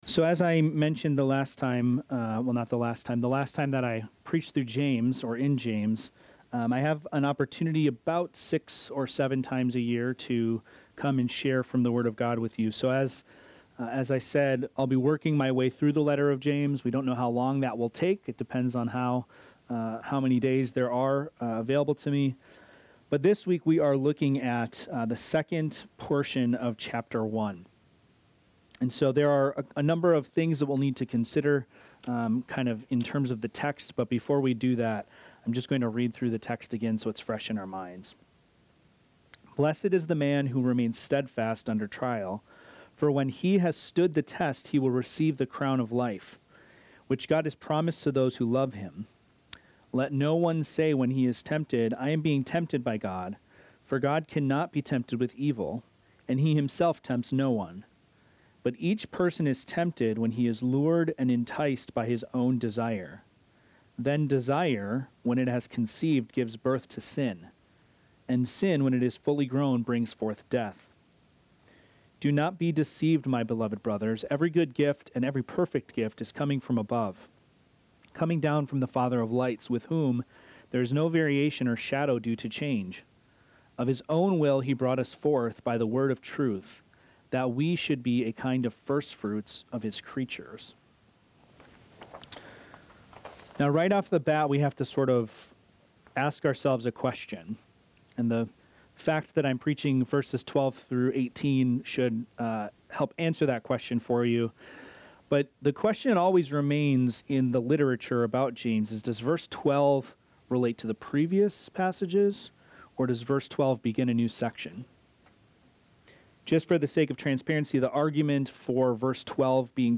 This sermon, based on James 1:12–18, examines trials, temptations, and the unchanging nature of God. The preacher begins by discussing the concept of steadfastness under trial, emphasizing that trials are given by God to strengthen faith and produce spiritual maturity.